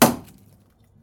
wood_chop.mp3